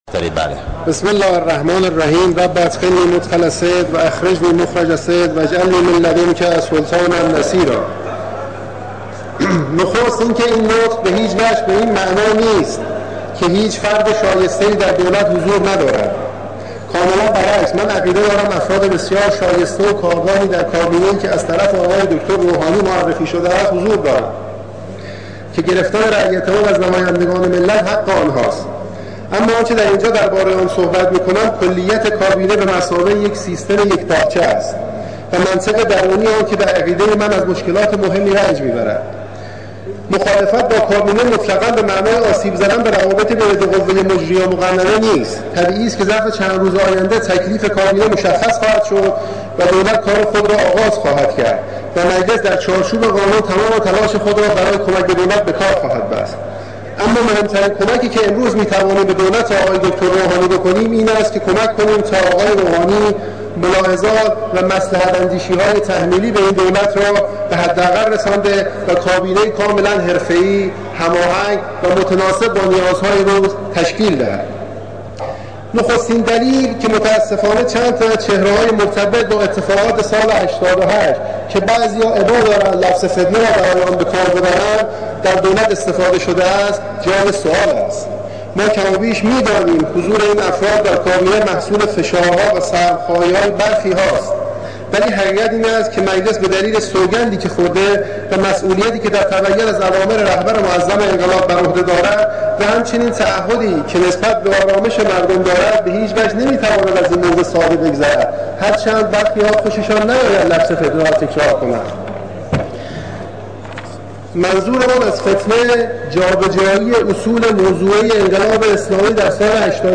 به گزارش «نماینده»، مهرداد بذرپاش نماینده تهران به عنوان نماینده مخالف کلیات برنامه دولت یازدهم پشت تریبون قرار گرفت.
دریافت فایل نطق بذرپاش در مخالفت با کلیات کابینه با حجم